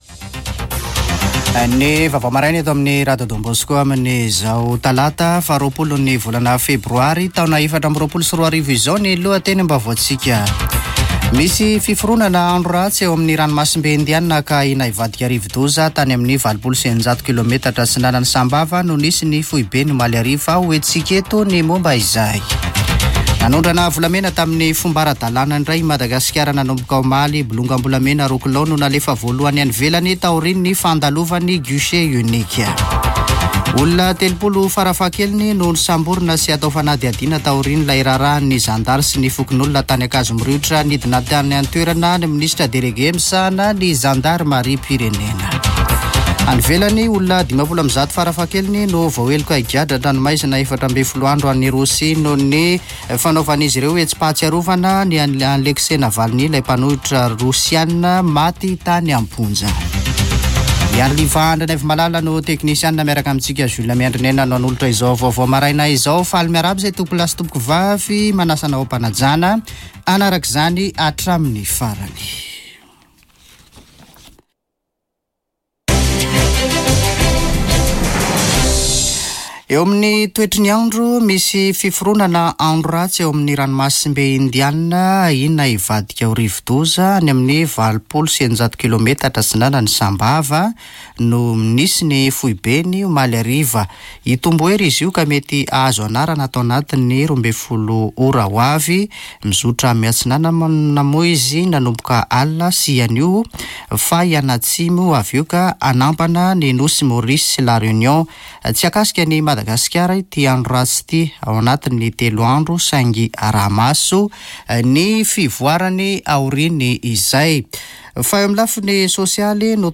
[Vaovao maraina] Talata 20 febroary 2024